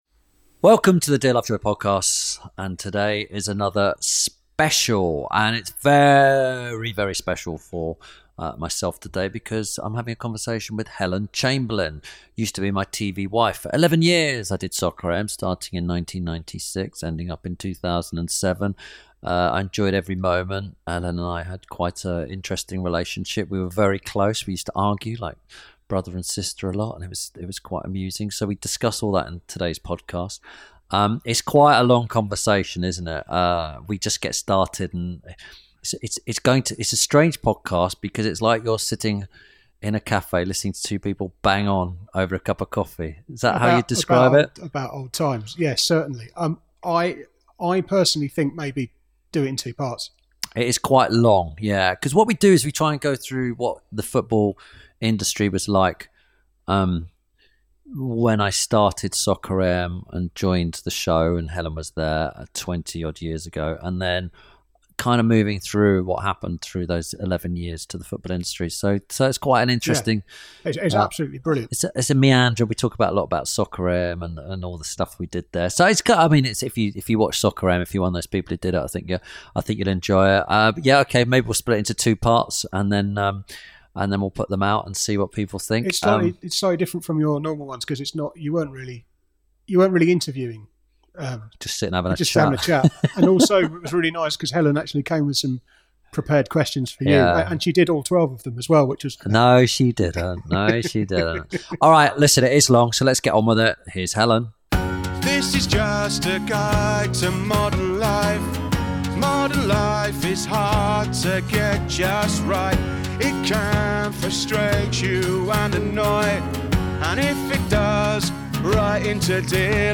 This week Tim Lovejoy talks to presenter and his erstwhile co-host Helen Chamberlain. In this part they discuss how Helen began at Soccer AM, how football and the program changed over the years, and her love of darts.